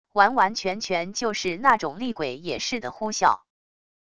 完完全全就是那种厉鬼也似的呼啸wav音频